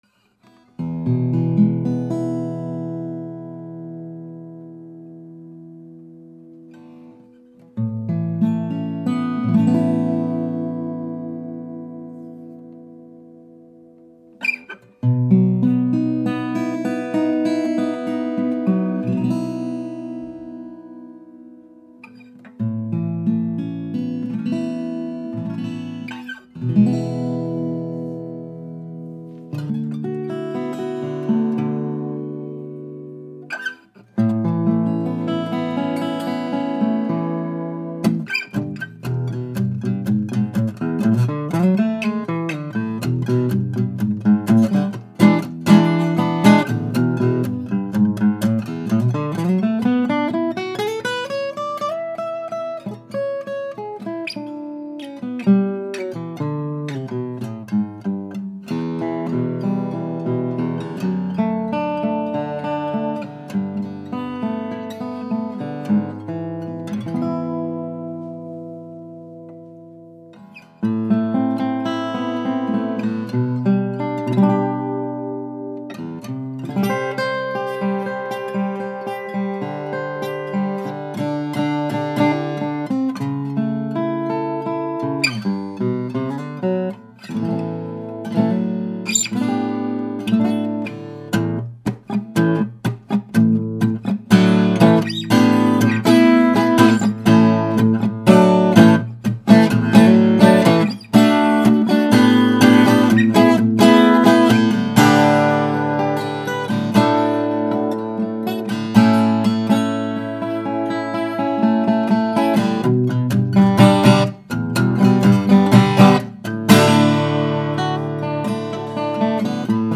Stunning Martin 00-21 Golden Era in Adirondack Spruce and East Indian Rosewood.
Open sounding, great attack with clear highs, nice bass tones and resonant.